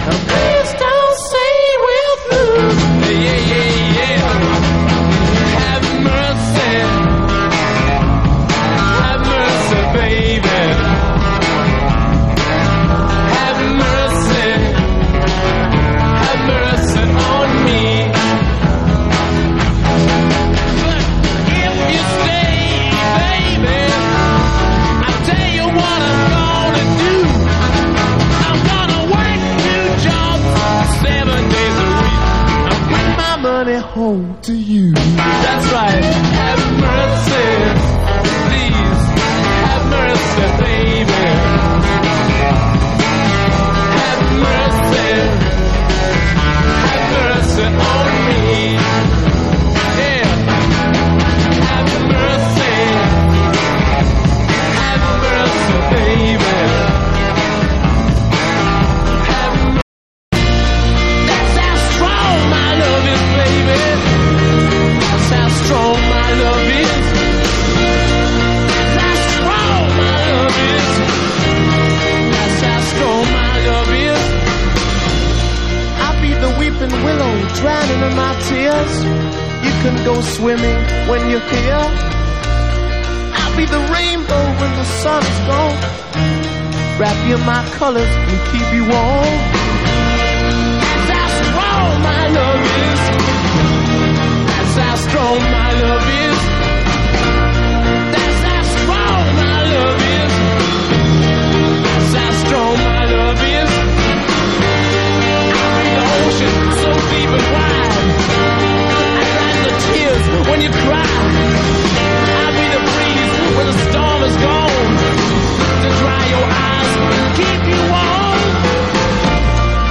JAZZ / EASY LISTENING JAZZ / HARP JAZZ
繊細なタッチと柔らかな響きでスタンダードを彩るハープ・ジャズ作品で、清涼感あふれるアレンジが魅力。
優雅でロマンティックなサウンドに包まれる極上のラウンジ・ジャズ・アルバム。